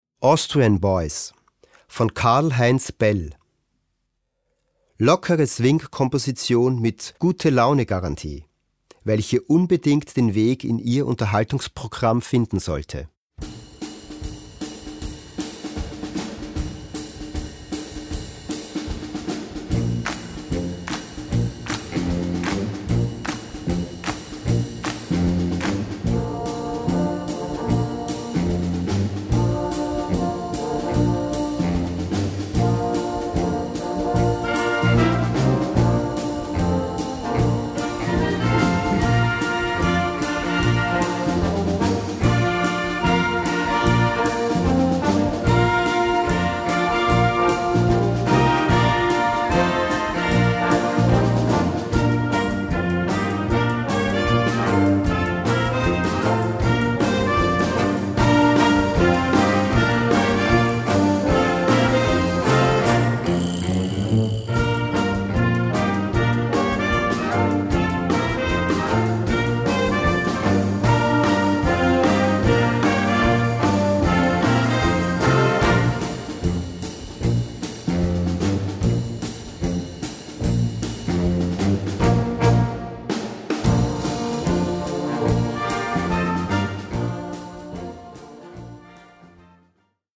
Gattung: Swing
Besetzung: Blasorchester